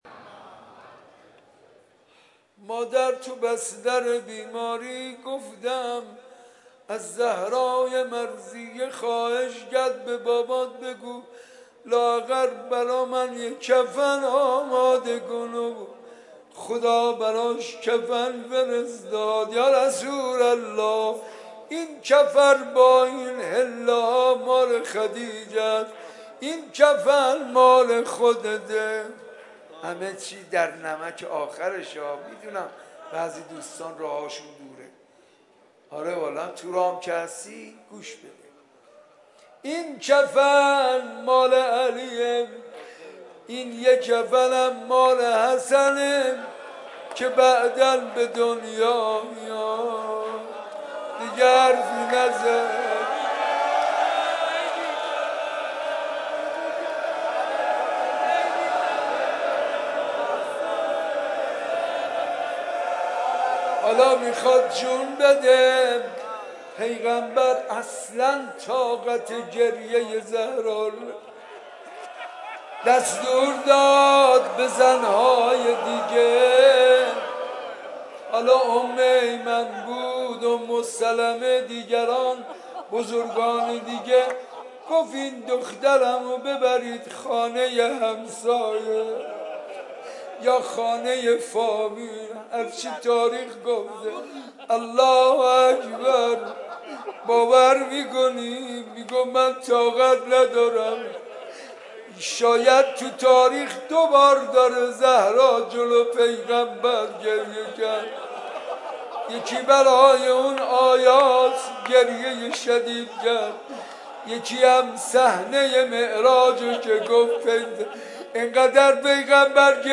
شب دهم ماه مبارک رمضان93